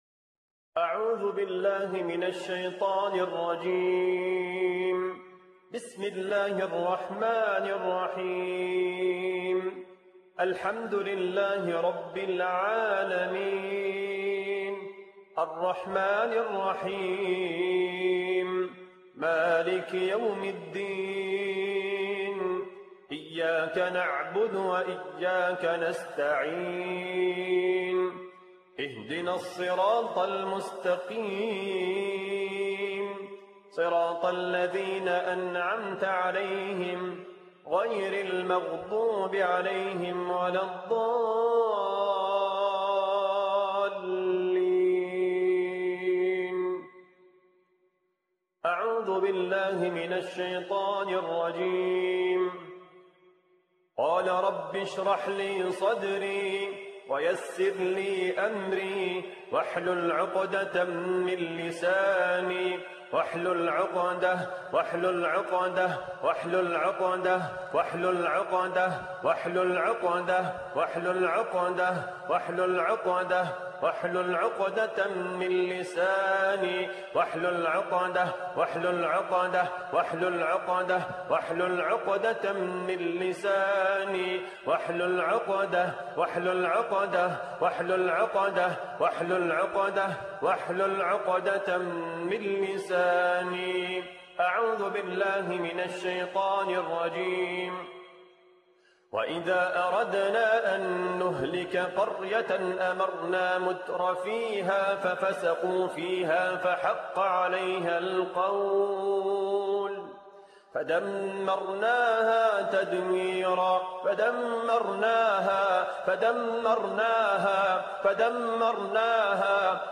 কাঁধ, পিঠ ও মেরুদণ্ডের যাদুর গিঁট নষ্টের রুকইয়াহ